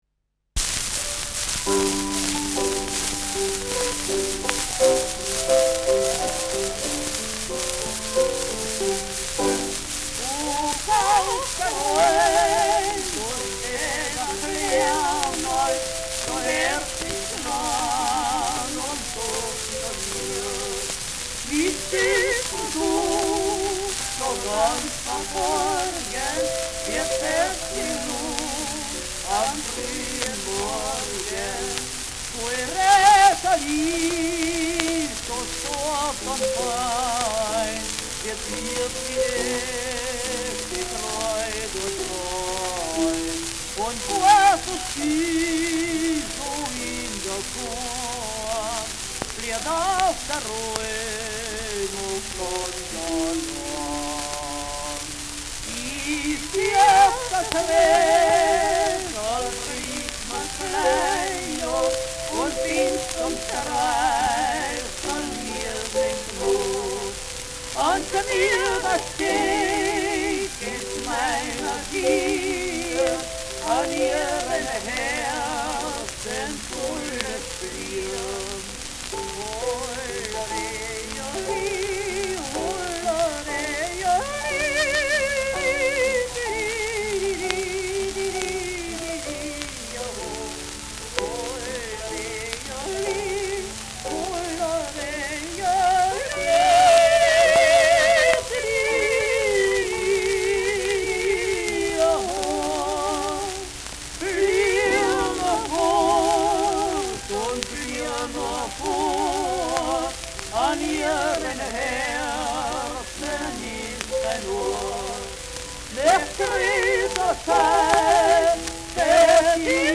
Schellackplatte
präsentes Rauschen : präsentes Knistern : abgespielt : gelegentliches „Schnarren“ : vereinzeltes Knacken
Erste Oberösterreichische Sänger- und Jodler-Gesellschaft (Interpretation)